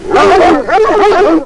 Dogs Cackling Sound Effect
Download a high-quality dogs cackling sound effect.
dogs-cackling.mp3